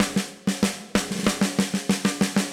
AM_MiliSnareB_95-03.wav